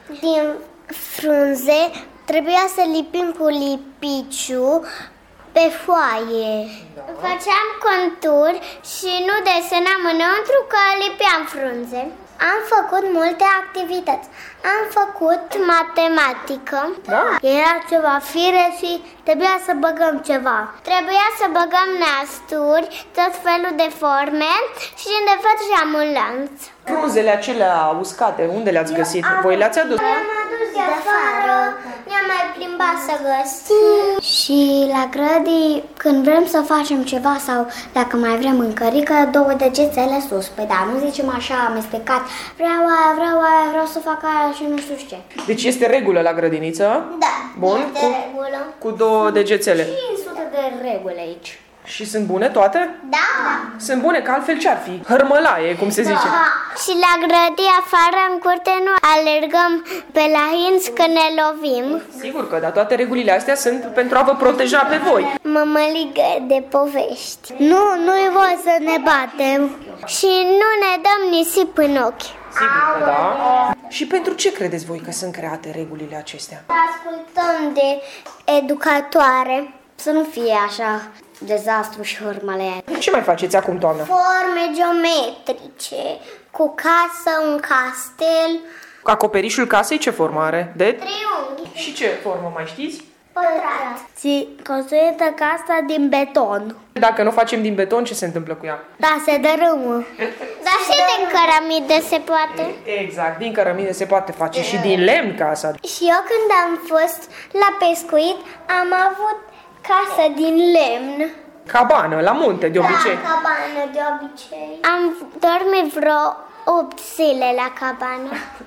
Preșcolarii din grupa mare a Iepurașilor, de la Grădinița „Dumbrava minunată”, povestesc despre noile activități de la grădi: lipesc frunze colorate, confecționează coliere din nasturi și construiesc case, exersând forme geometrice și noțiuni de matematică.